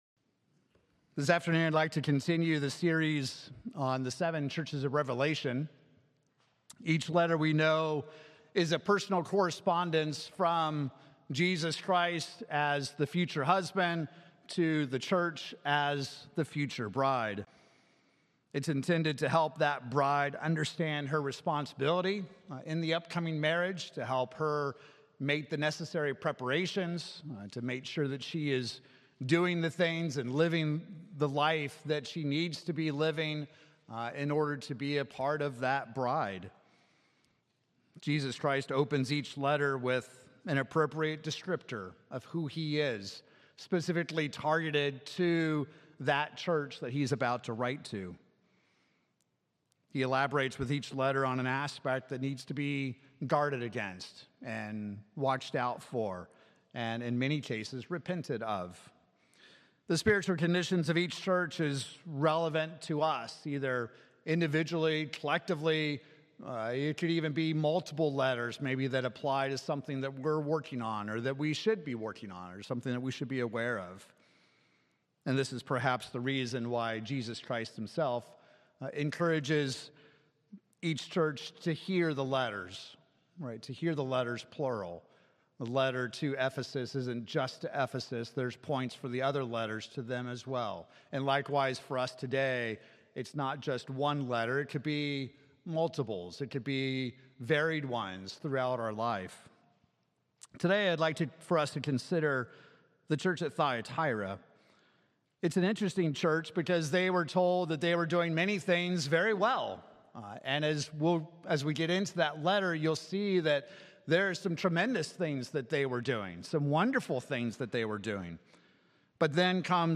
Sermons
Given in Oklahoma City, OK Tulsa, OK